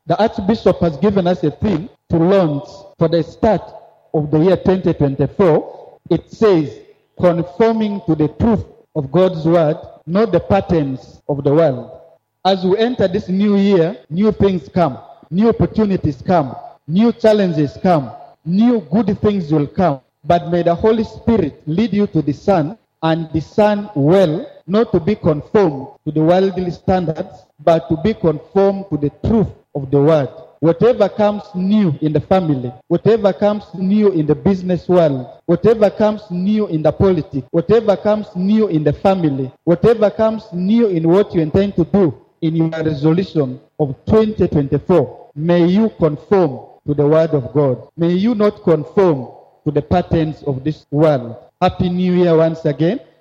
As the clock struck midnight on January 1, 2024, the Emmanuel Cathedral in Mvara, Arua city, echoed with a powerful New Year sermon by the Rt. Rev. Charles Collins Andaku, the esteemed Bishop of the Diocese of Madi-West Nile. In his inspiring message, the Bishop urged the public to embark on the new year with unwavering faith and a strong sense of confrontation against life's challenges.
01_01 - BISHOP ON NEW YEAR .mp3